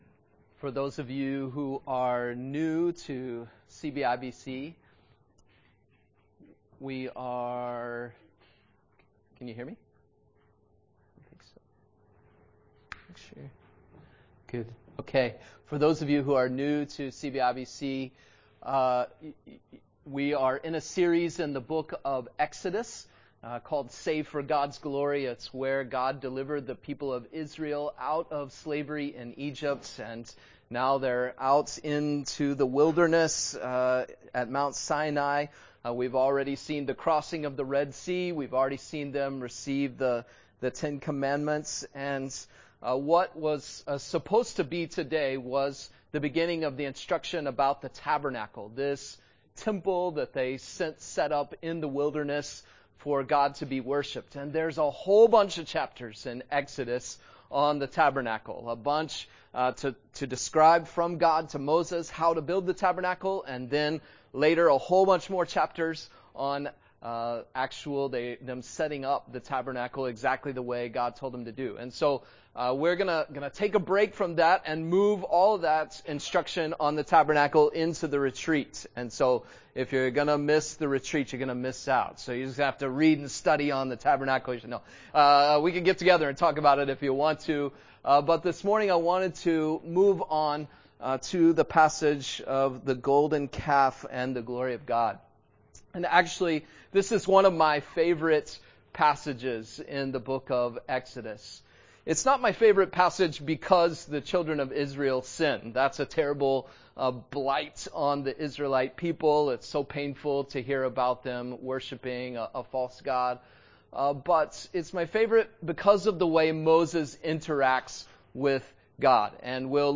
Join us for worship this Sunday at 11:00 am.
Speaker